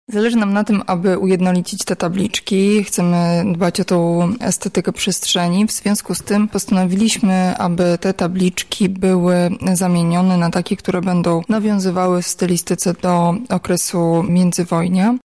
dźwięk  mówi